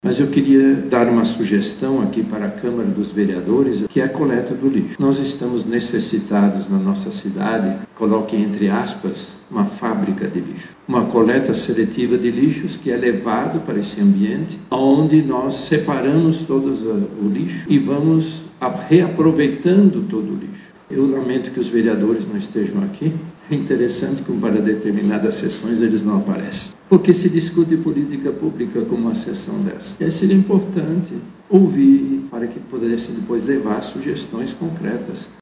O evento foi realizado no plenário da Câmara Municipal de Manaus nesta quinta-feira, 27 de março.